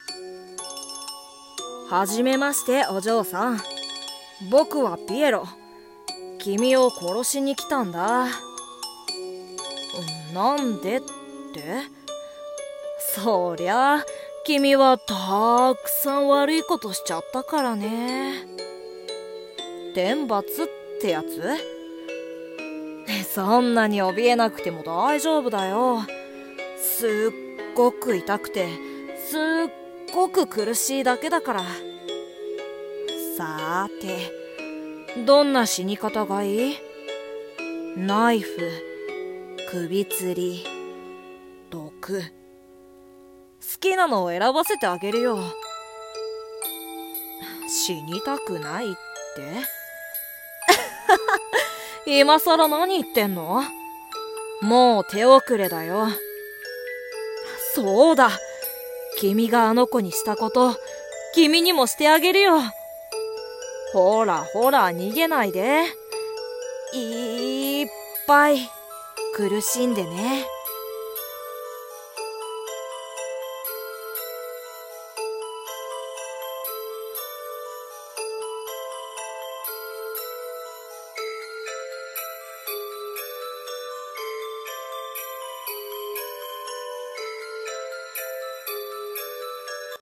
【声劇】道化師